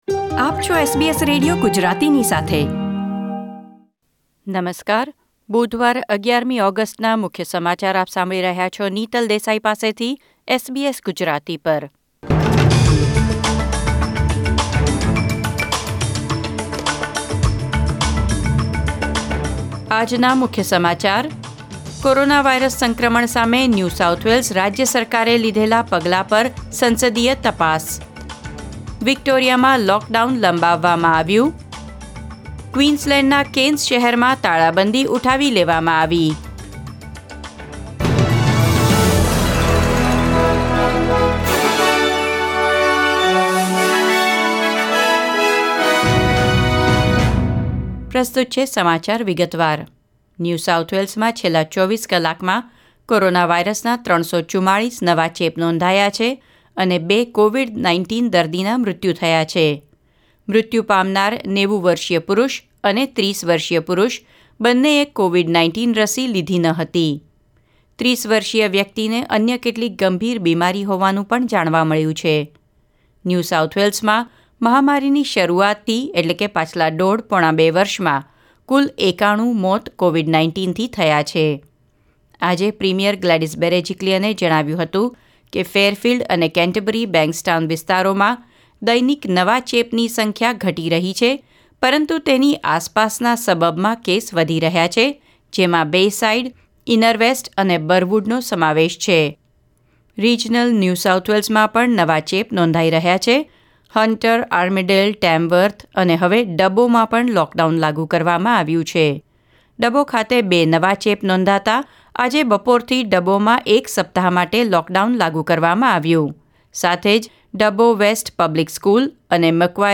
SBS Gujarati News Bulletin 11 August 2021